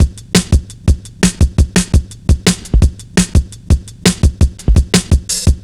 Index of /90_sSampleCDs/Zero-G - Total Drum Bass/Drumloops - 3/track 62 (170bpm)